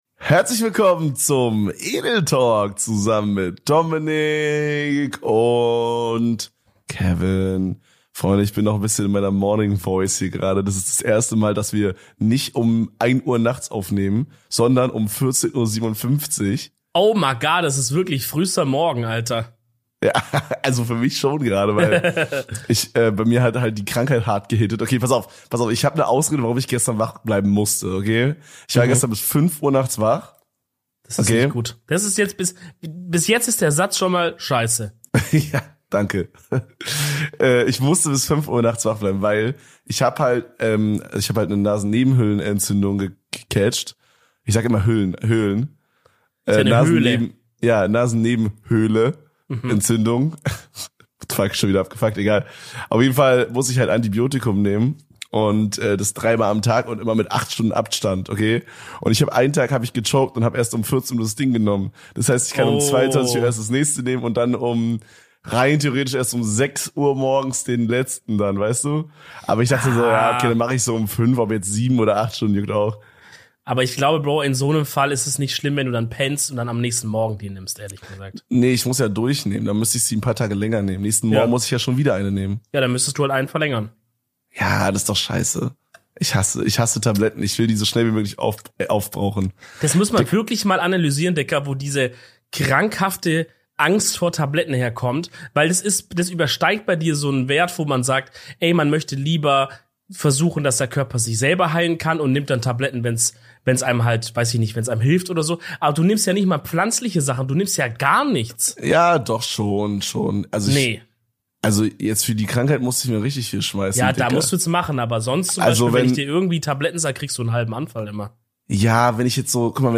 Verschnupft und verhustet aber trotzdem Bomben Stimmung, das kann doch nur die neue Folge Edeltalk sein.